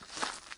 Rock Foot Step 5.wav